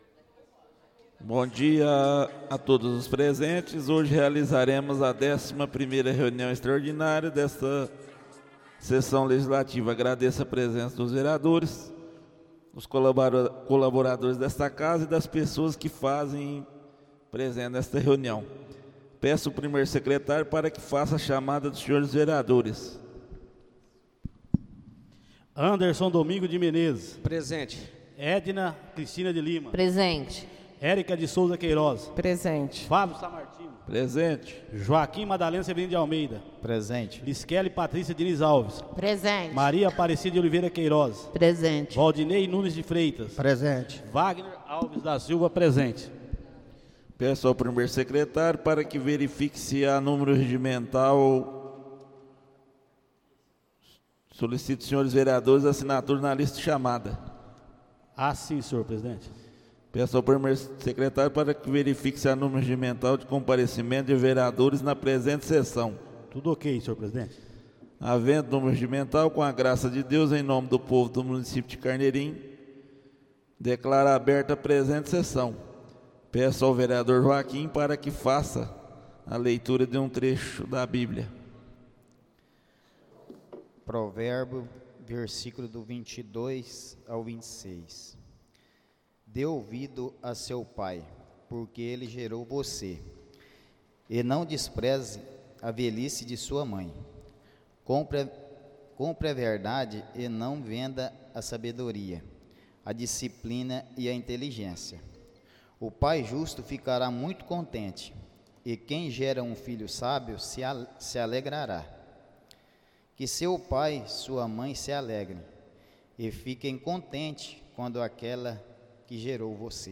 Áudio da 11.ª reunião extraordinária de 2025, realizada no dia 29 de setembro de 2025, na sala de sessões da Câmara Municipal de Carneirinho, Estado de Minas Gerais.